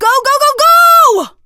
max_start_vo_02.ogg